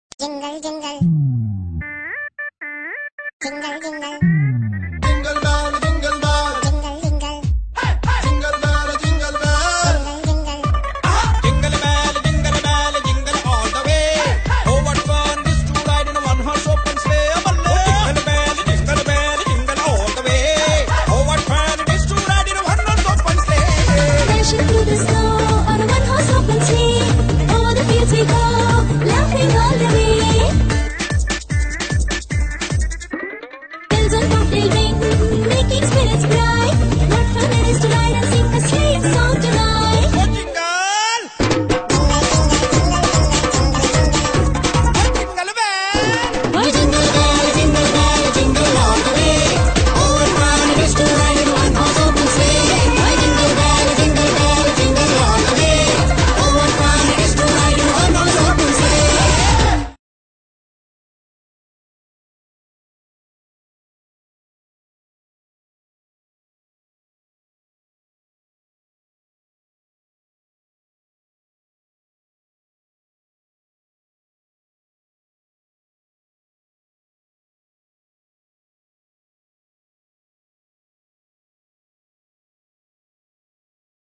While I cannot recall my childhood Punjabi carols, here is a close proxy of Jingle Bells the way it would be sung in the Punjab.
indian-version-jingle-bells-jingle-bells-punjabi-style.mp3